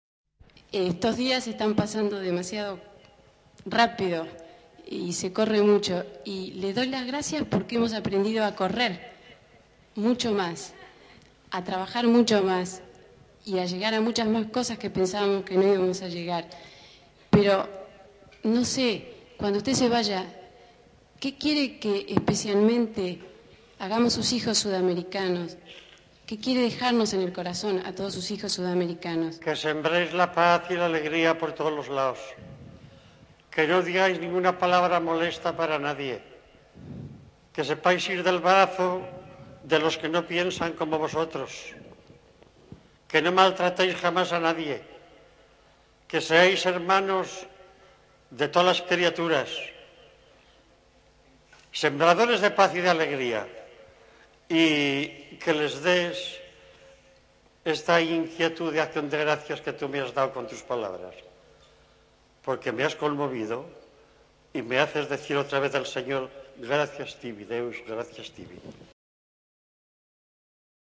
Sembradores de paz y de alegría. (1’ 11”) Teatro general San Martín. Buenos Aires (Argentina)